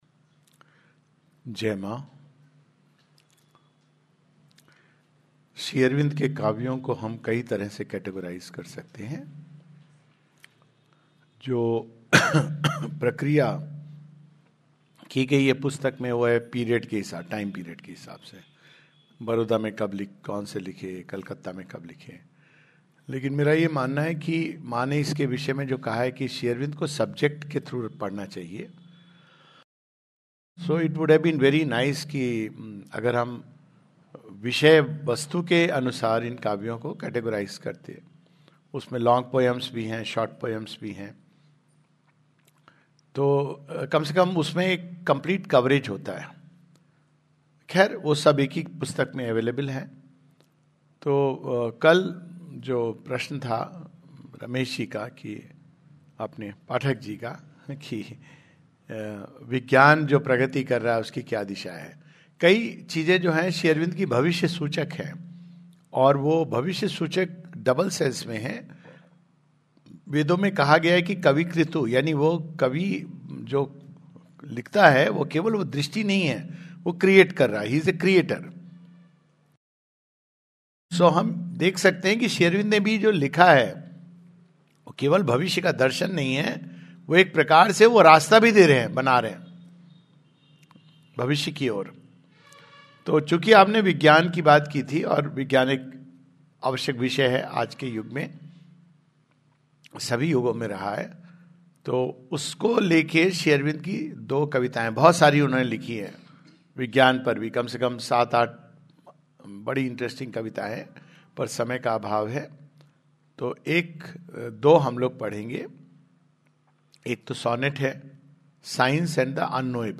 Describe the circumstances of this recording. at Ratlam, MP.